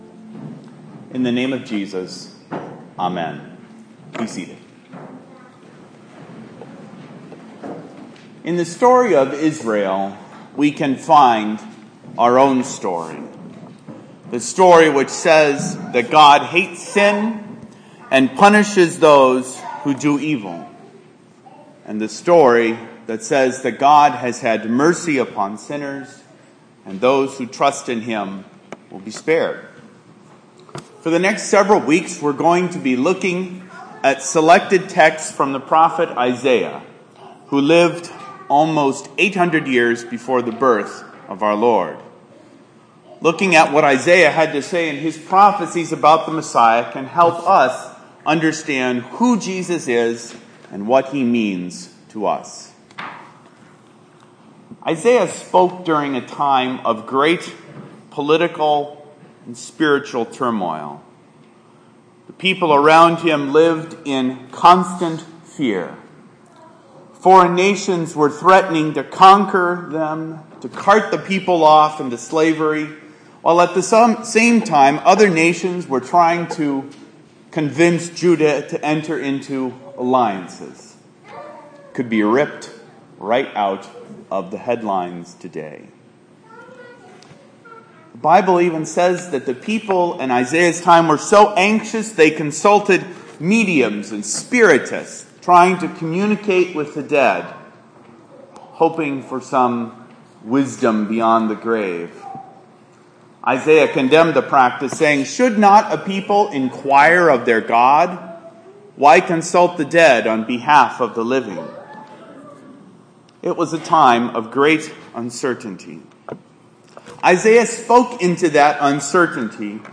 Â The sermon is based on Isaiah 9:1-7.